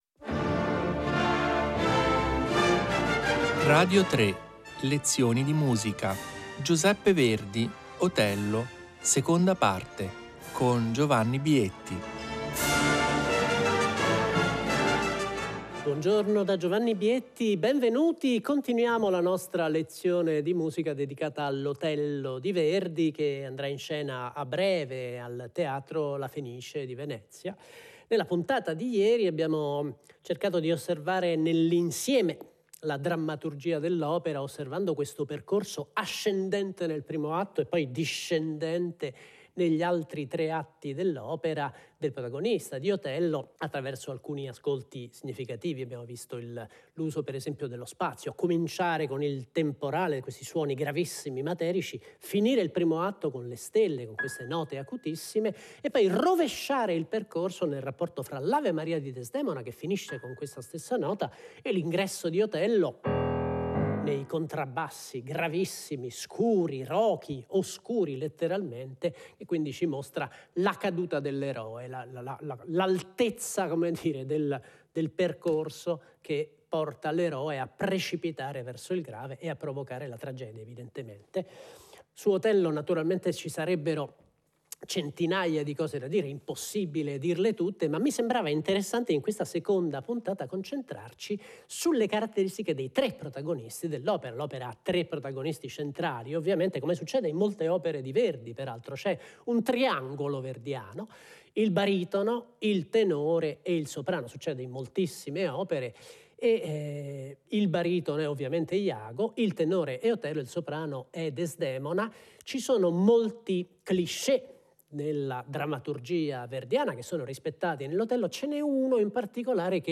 1 3. Johannes Brahms, Sonata n. 3 in re minore op. 108 per violino e pianoforte 29:12